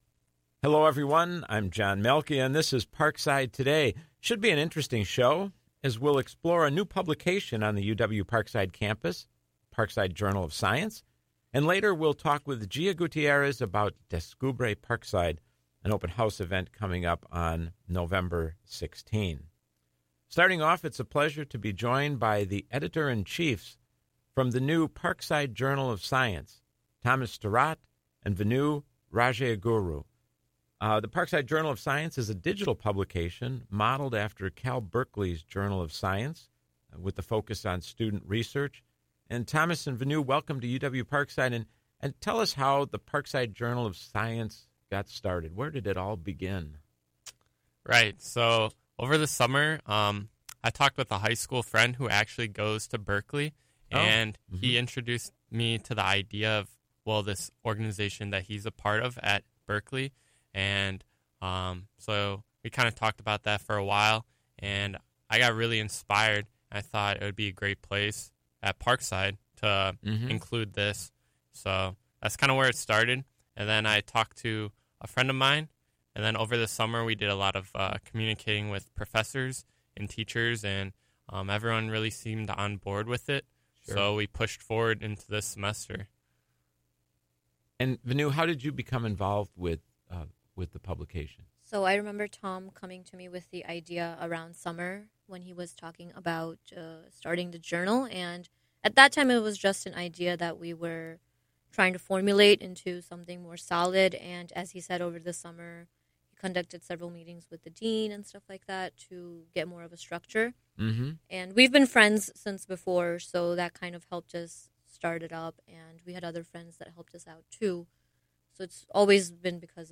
This show originally aired on Tuesday, November 5, at 4 p.m. on WIPZ 101.5 FM.